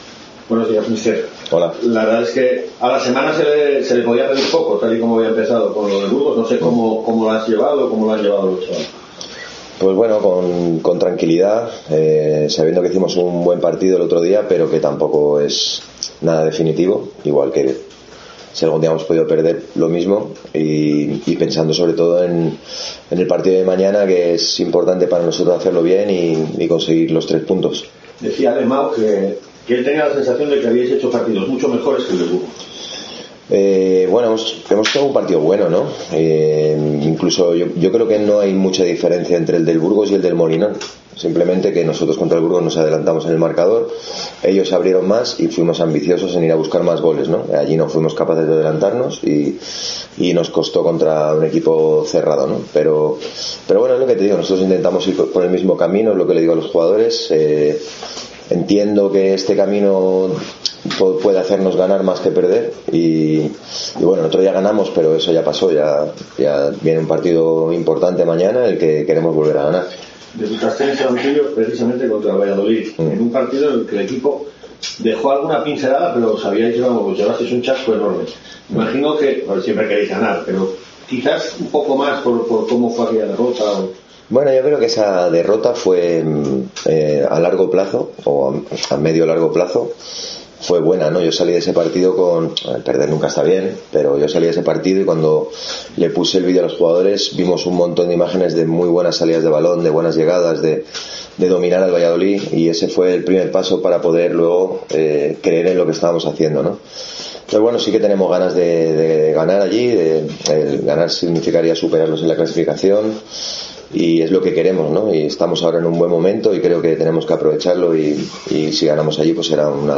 Convocatoria y rueda de prensa de Carrión